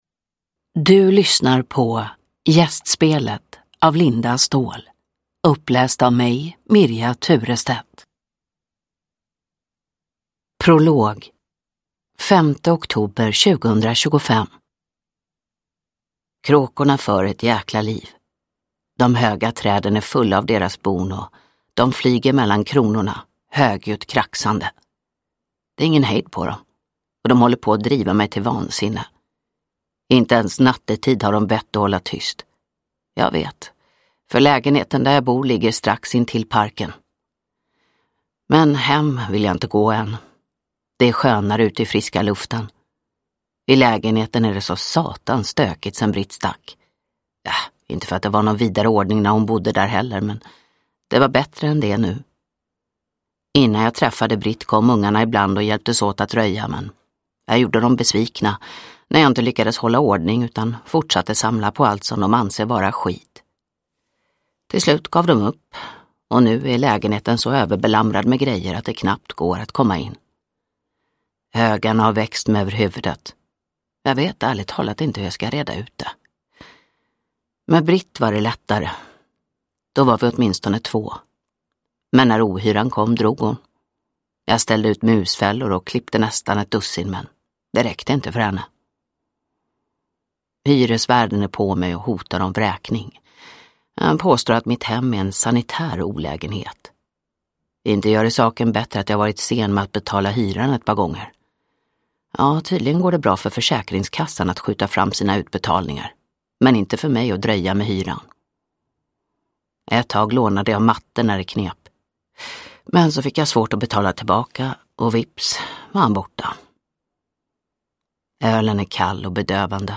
Gästspelet (ljudbok) av Linda Ståhl